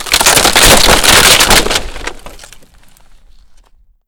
trap-collapse.wav